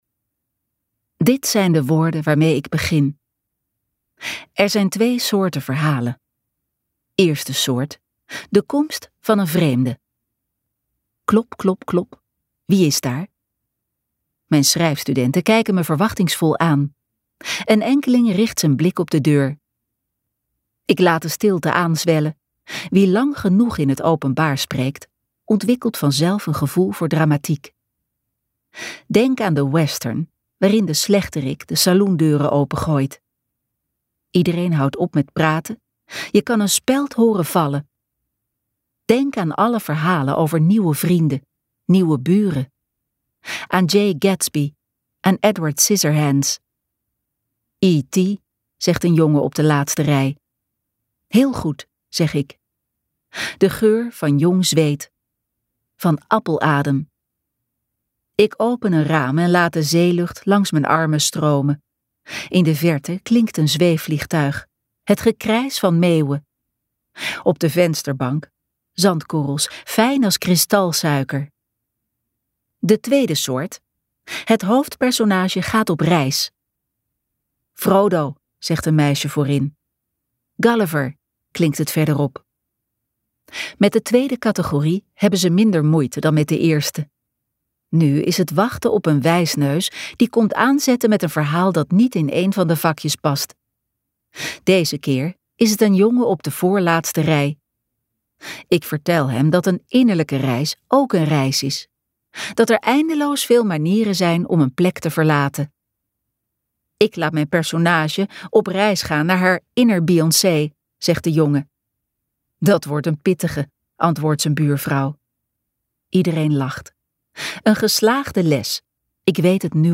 Ambo|Anthos uitgevers - Tot het glinstert luisterboek